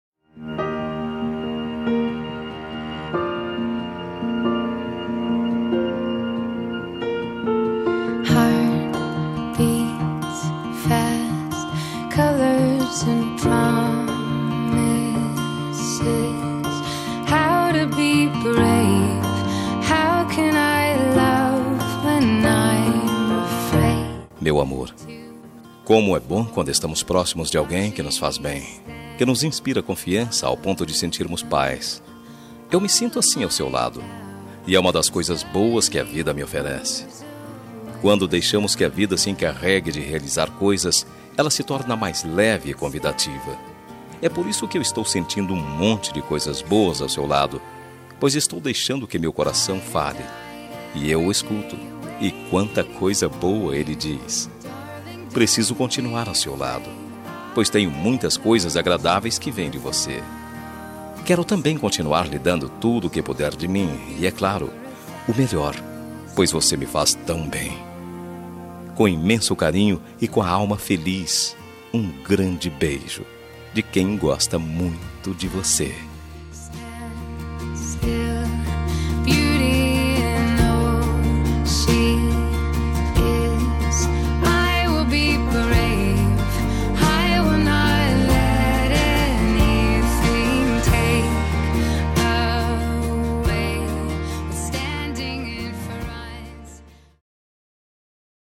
Telemensagem Ficante – Voz Masculina – Cód: 5452 – Linda
5452-ficante-masc.m4a